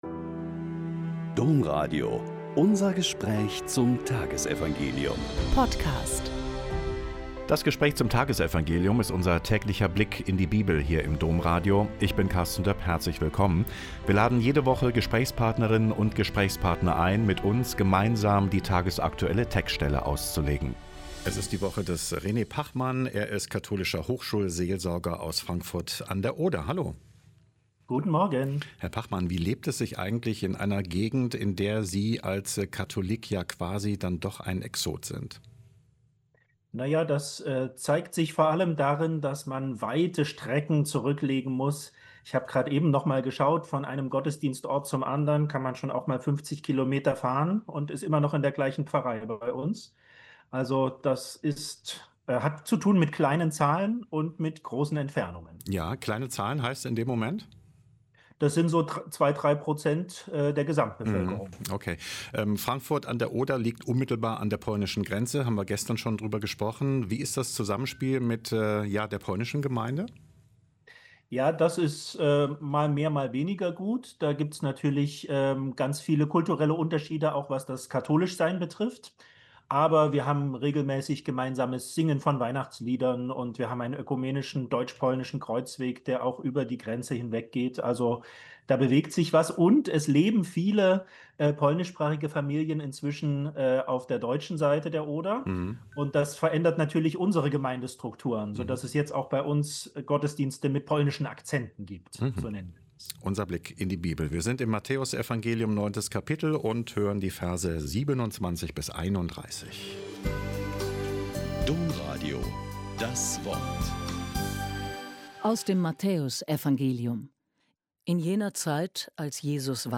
Mt 9,27-31 - Gespräch